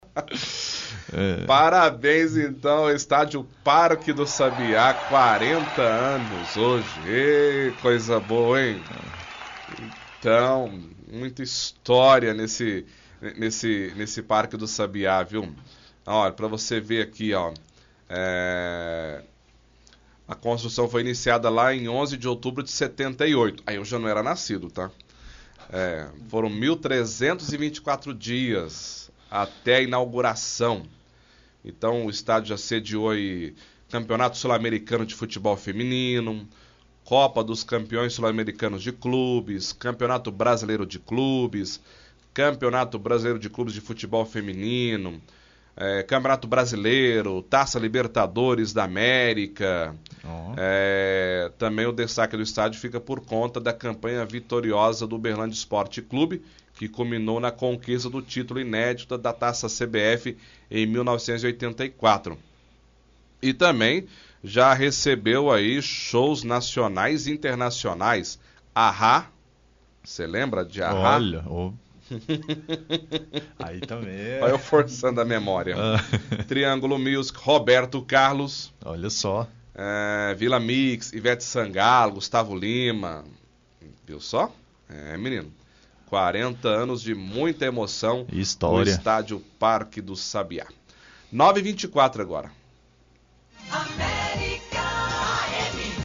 Leitura de Release